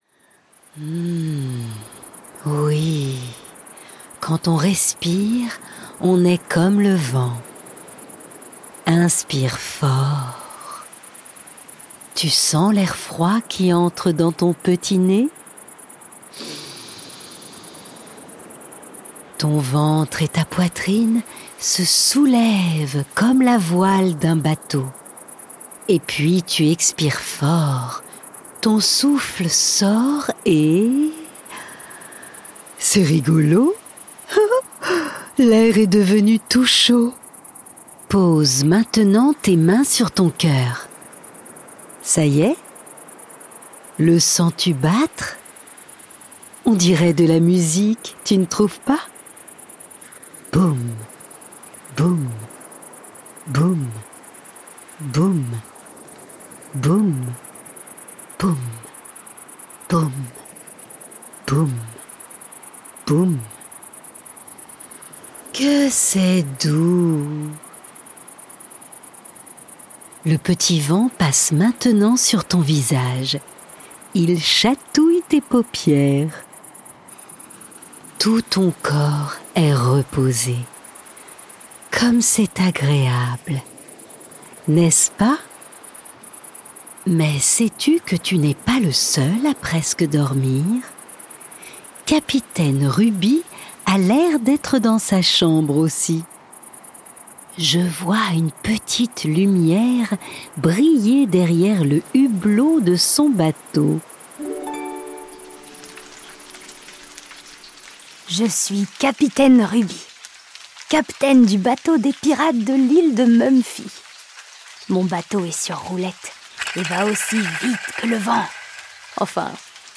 Détendez-vous au son des clochettes, de la pluie, des vagues et du vent avec Mumfie et ses amis!